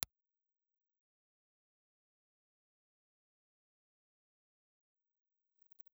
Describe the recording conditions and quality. Ribbon Impluse Response file of the Philips 9559 with bass cut inductor in place Philips_9559_Bass_Cut_IR.wav